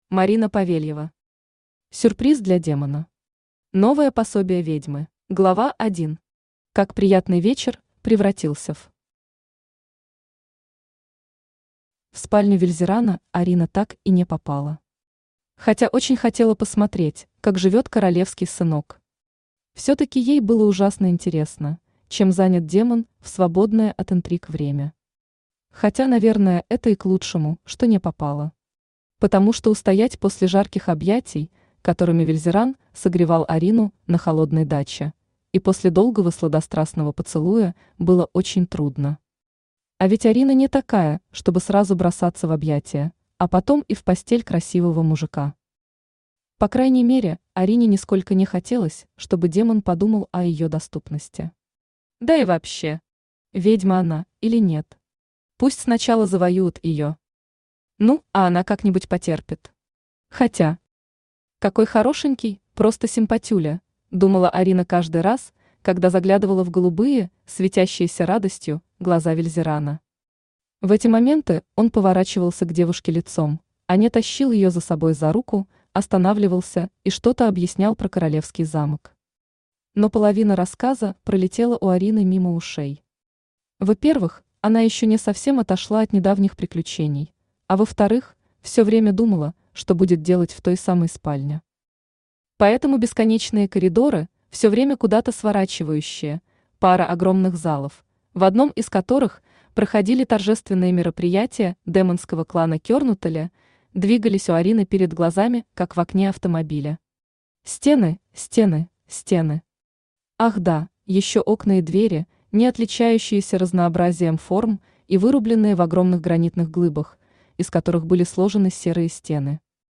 Аудиокнига Сюрприз для демона. Новое пособие ведьмы | Библиотека аудиокниг
Новое пособие ведьмы Автор Марина Павельева Читает аудиокнигу Авточтец ЛитРес.